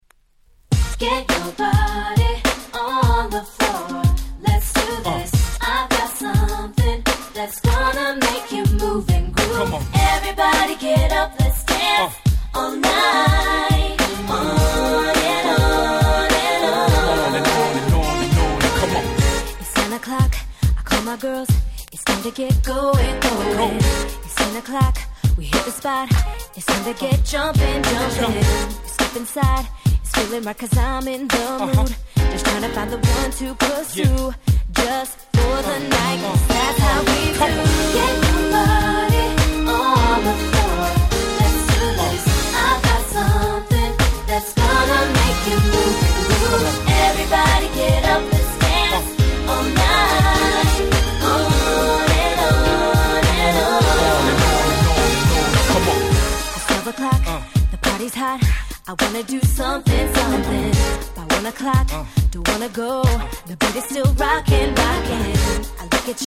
※試聴ファイルはWhite盤から録音したものです。